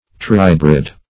Search Result for " trihybrid" : The Collaborative International Dictionary of English v.0.48: Trihybrid \Tri*hy"brid\, n. (Biol.) A hybrid whose parents differ by three pairs of contrasting Mendelian characters.